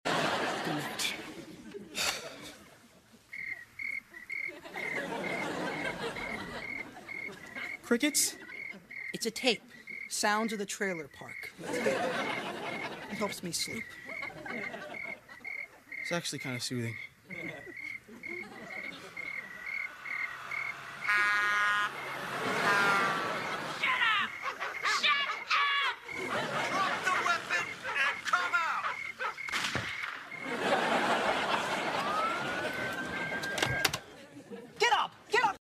The real white noise. sounds sound effects free download